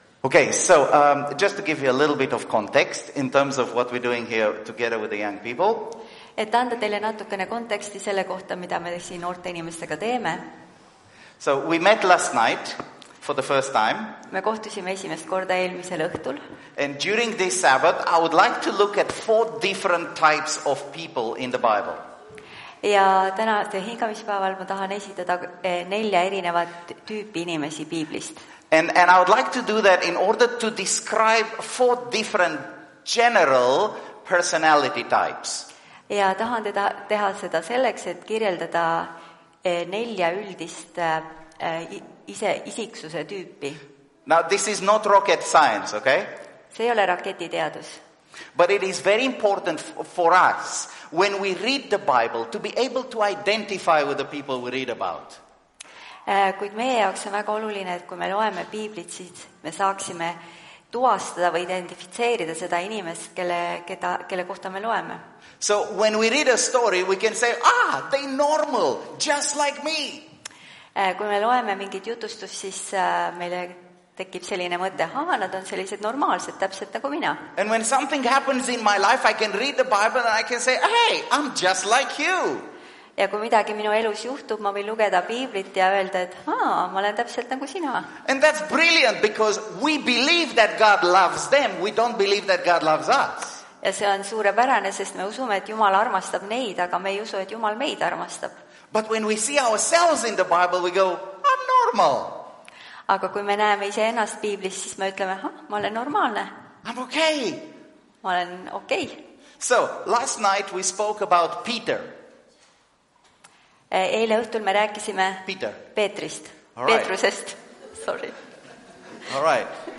Tartu adventkoguduse 03.05.2025 hommikuse teenistuse jutluse helisalvestis.